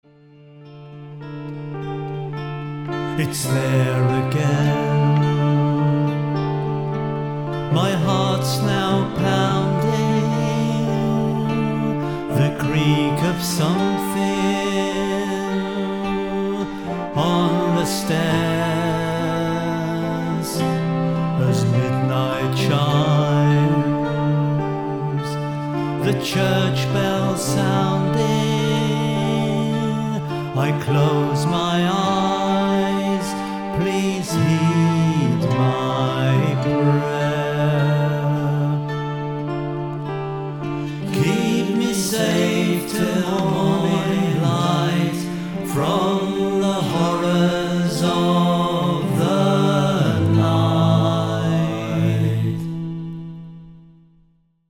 demo mix